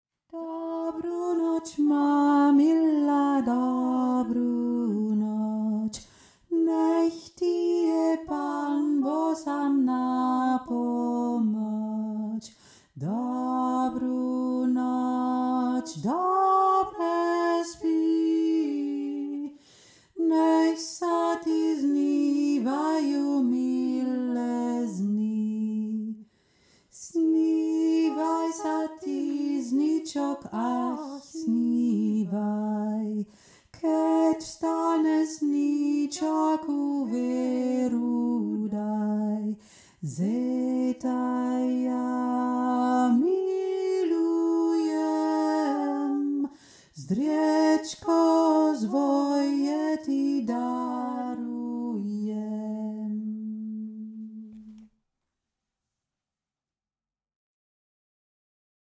Dobru noc (slovakisches Wiegenlied)
Dobru noc Hauptstimme